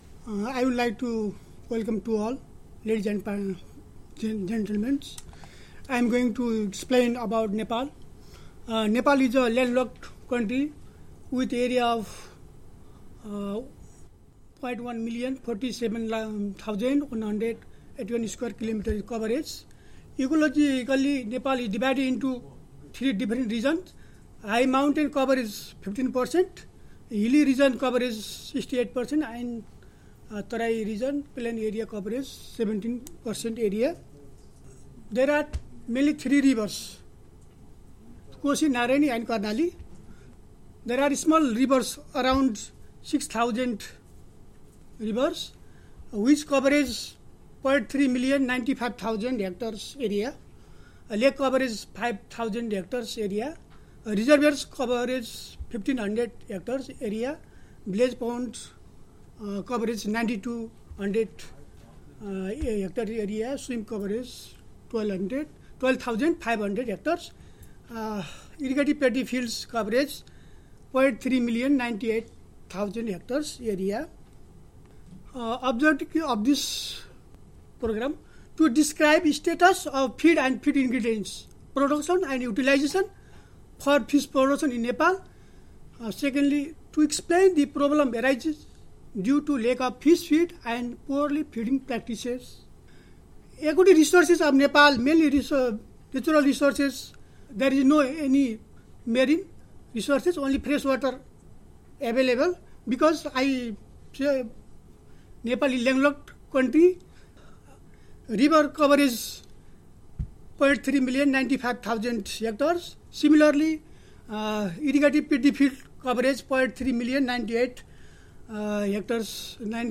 A regional consultation on aquaculture feed production and use in Asia-Pacific was held from 7-9 March 2017.
This collection contains audio recordings of the technical presentations made by experts, international organisations, the private sector and governments in the region.